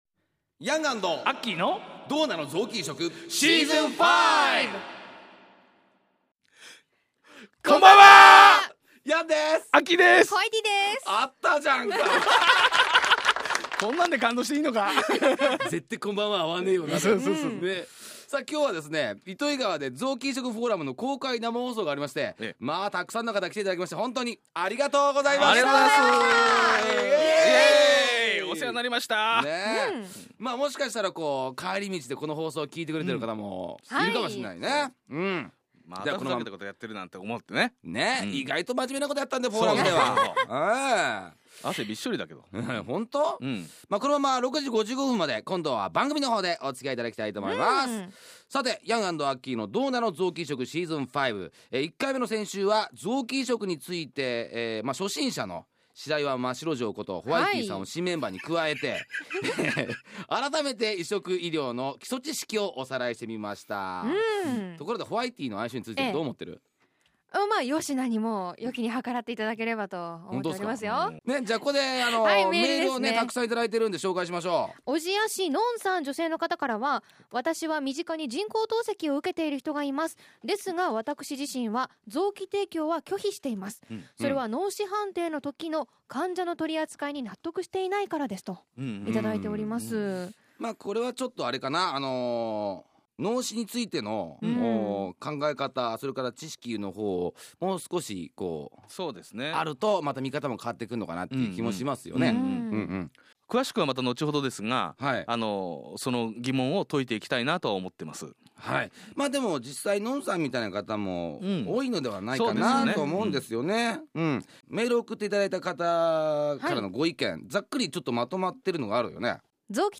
FM-NIIGATA特別番組
※BGMやリクエスト曲、CMはカットしています。